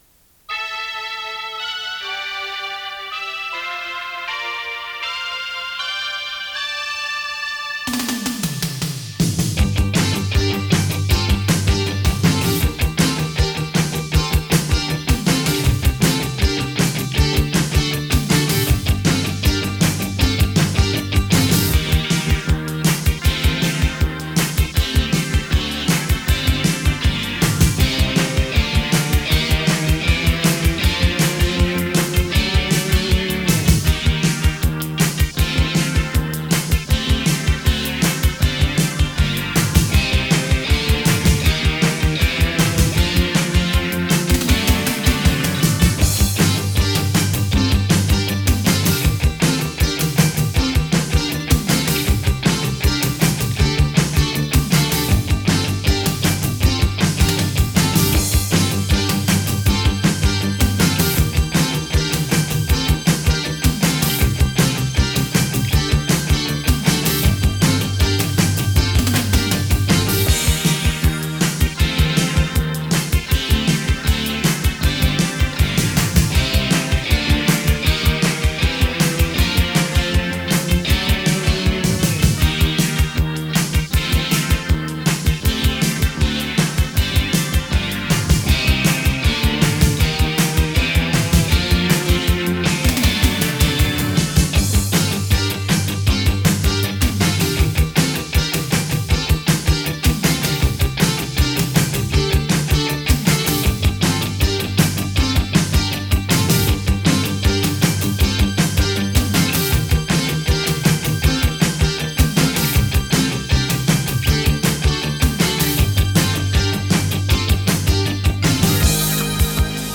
Sing to the Karaoke Version